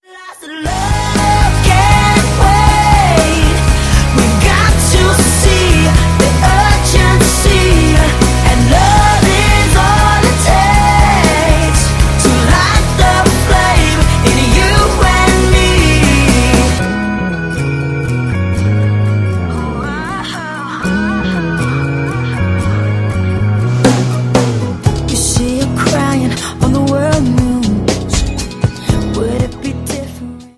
facettenreichen Soul-Pop nahtlos daran an.
• Sachgebiet: Pop